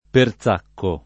[ per Z# kko ]